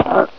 /hl2/sound/npc/combine_soldier/test/near/
pain1.ogg